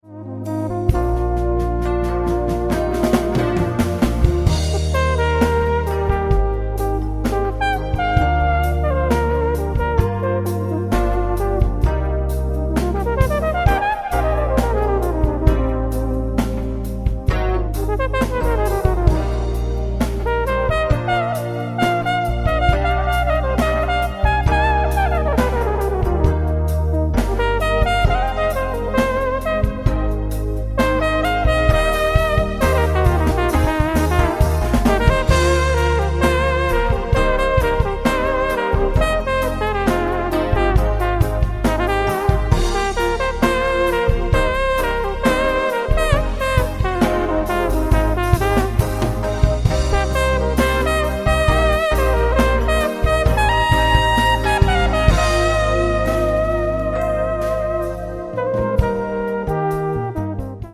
keyboards/left hand bass
drums
Crumar EVI